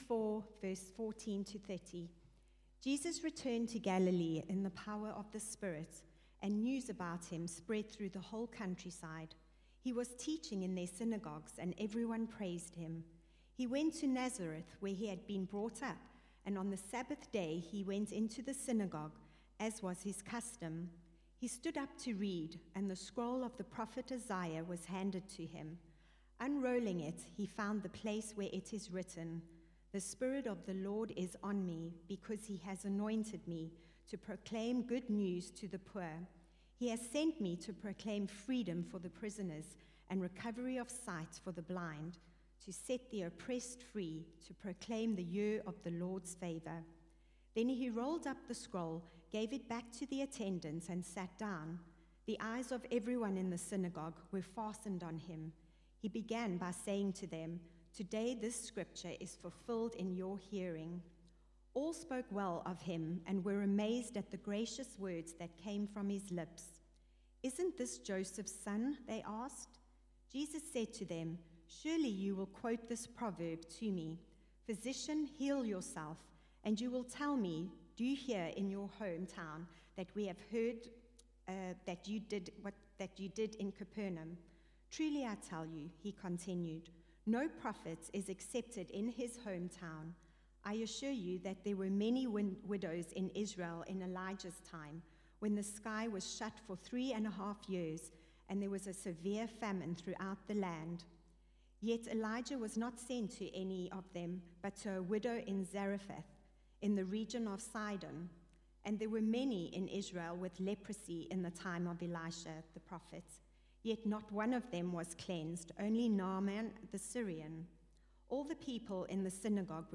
Media Library The Sunday Sermons are generally recorded each week at St Mark's Community Church.
Theme: Jesus Christ is the good news Sermon